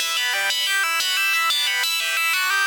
Index of /musicradar/shimmer-and-sparkle-samples/90bpm
SaS_Arp05_90-E.wav